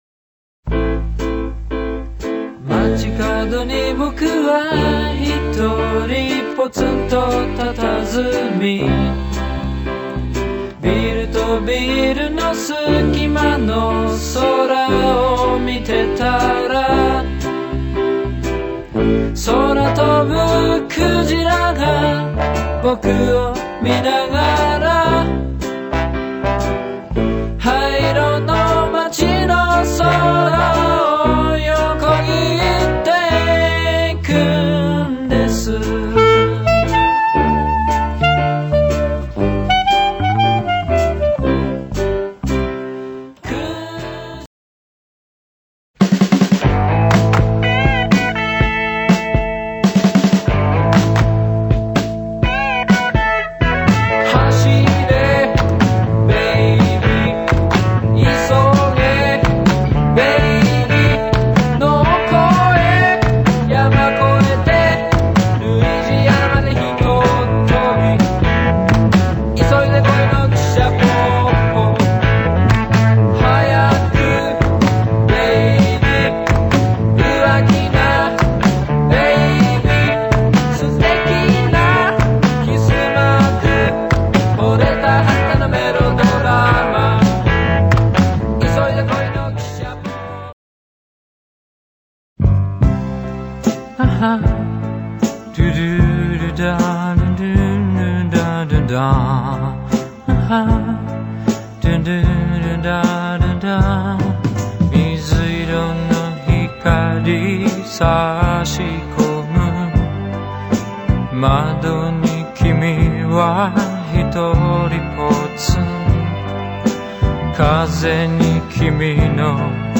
Includes singles and live recordings.